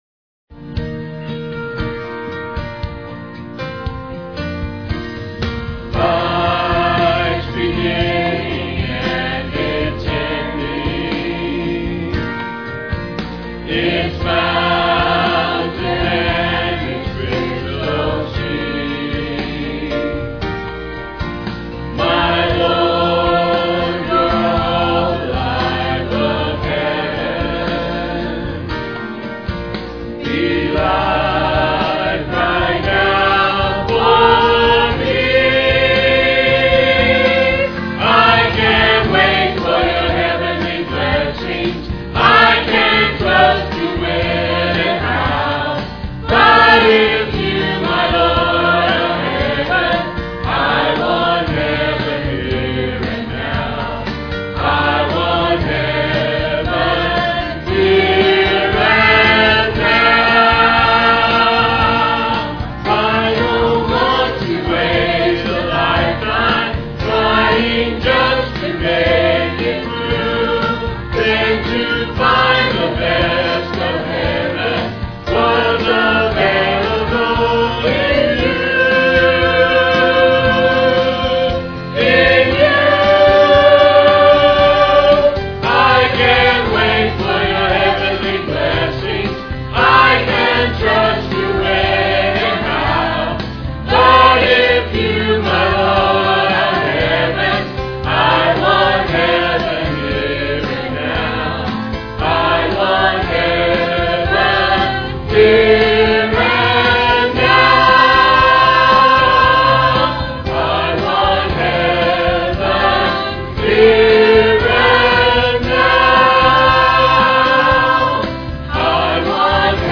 "Holy, Holy" words and music by Jimmy Owens. Piano duet